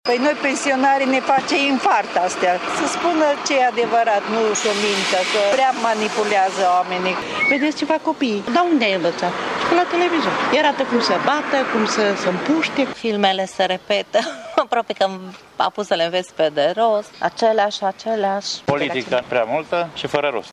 stiri-12-iul-vox-populi-tv.mp3